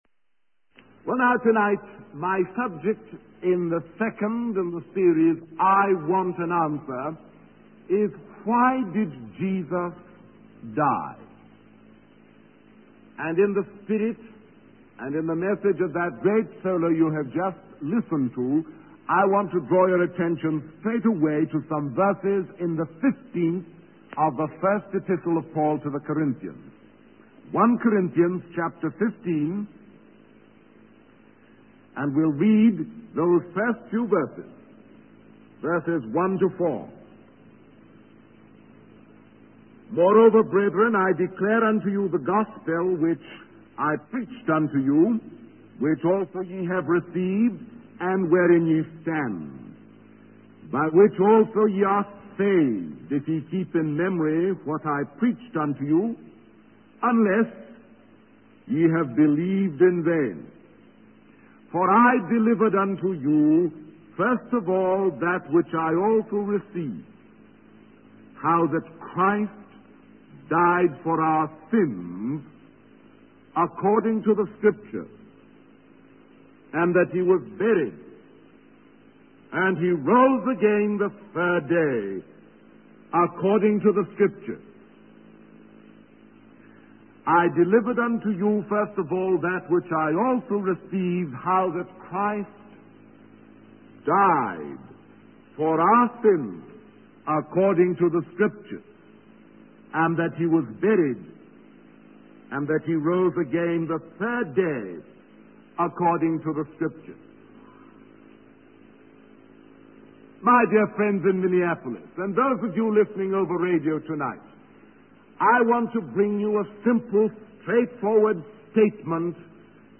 In this sermon, the preacher discusses the transformation of a girl who was living a carefree and frivolous life.